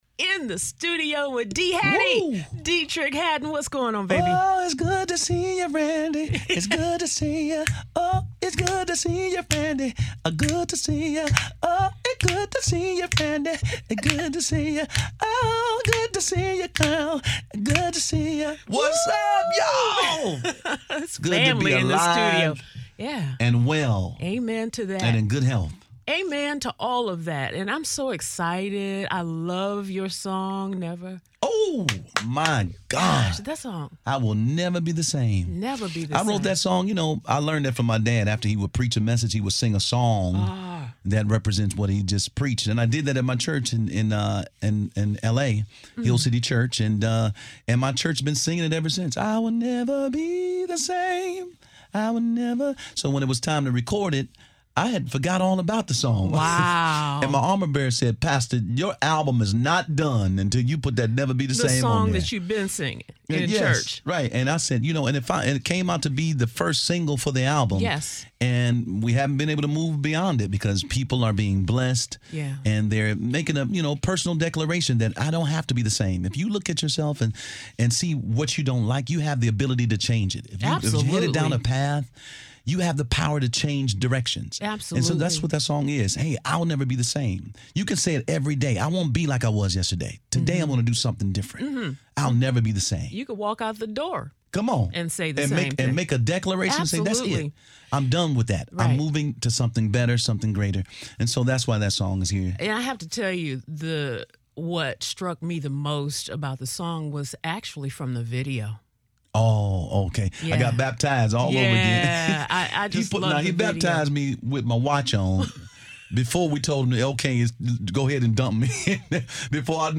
I had a chance to sit down with Deitrick Haddon to talk about his new hit, “Never Be The Same.” We chatted about the baptism from his music video and then had a great time diving deep into the exciting news about his first live recording in over 20 years.